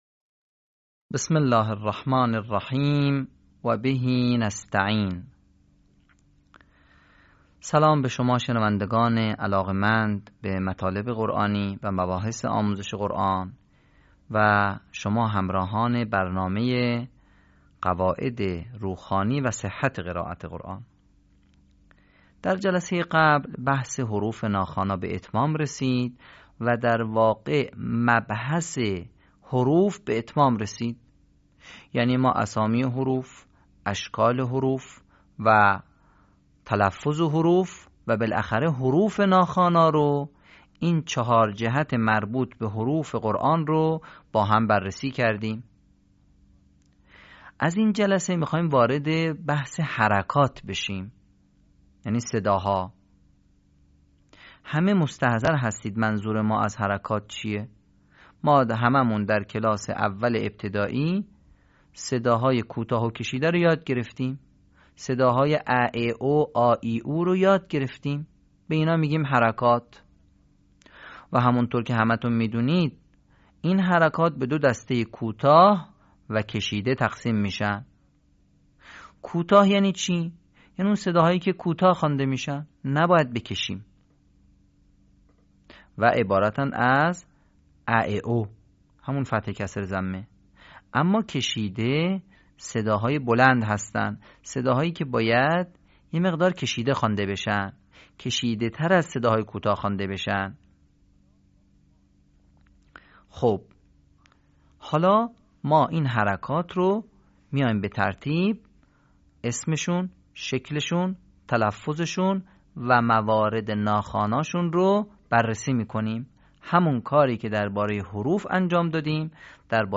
صوت | آموزش روخوانی «حروف عربی»